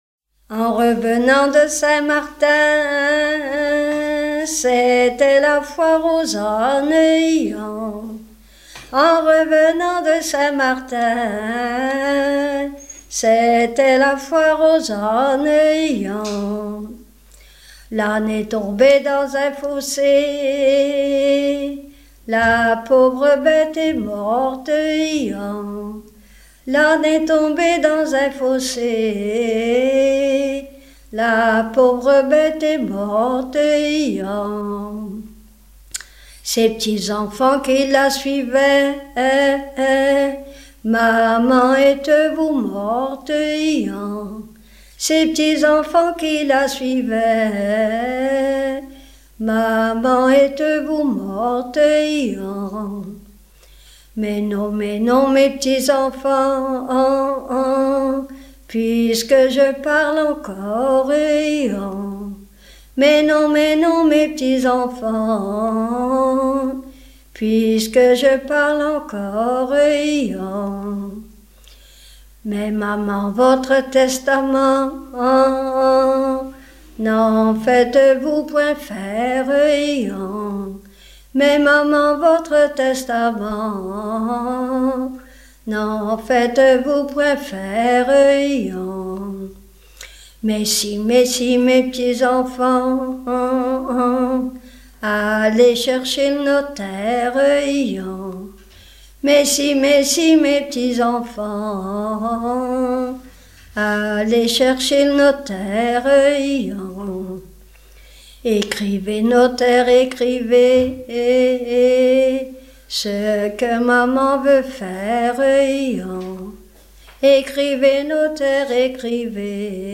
Mémoires et Patrimoines vivants - RaddO est une base de données d'archives iconographiques et sonores.
Fenouiller (Le)
Genre laisse